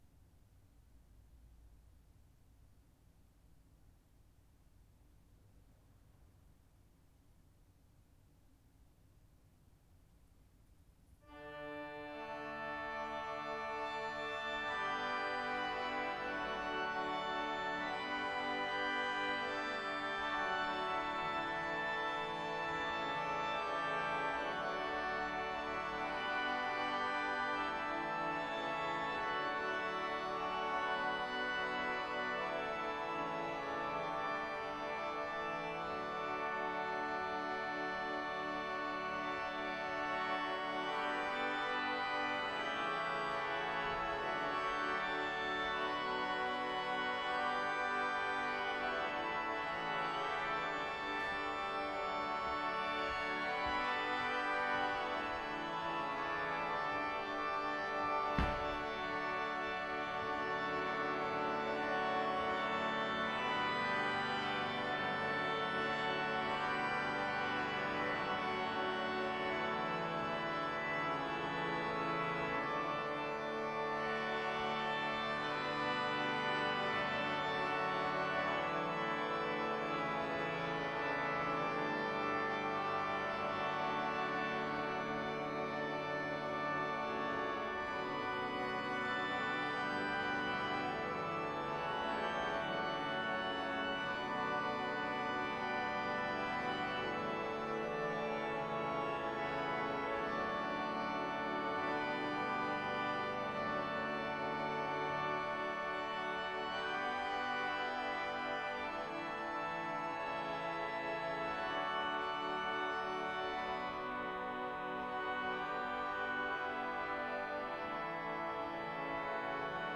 Bodø domkirke
Improv 66 - Lyd og stemmeprøve Liten impovisasjon i orkesterverket.  (Åpen kirke så noe støy)